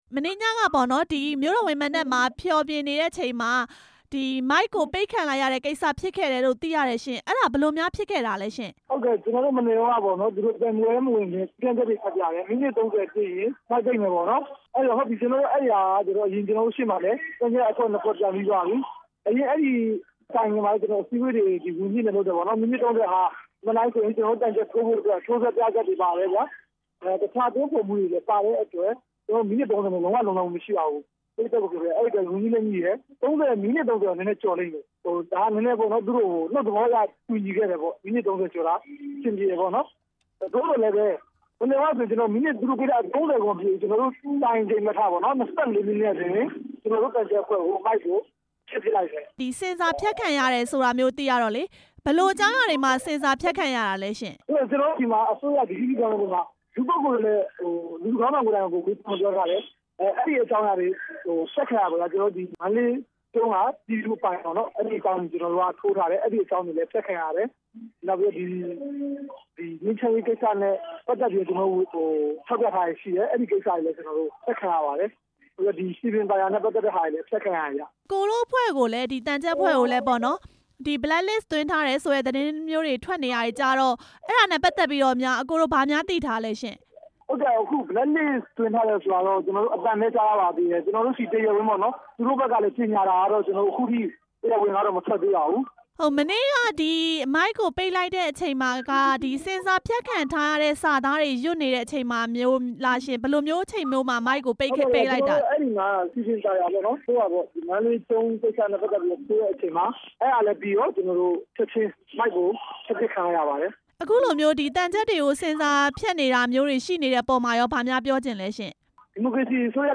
ရွှေလက်ညှိုးသံချပ်အဖွဲ့ မိုက်ပိတ်ခံရတဲ့အကြောင်း မေးမြန်းချက်